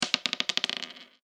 サイコロ・ダイス | 無料 BGM・効果音のフリー音源素材 | Springin’ Sound Stock
中くらいシングルダイス1.mp3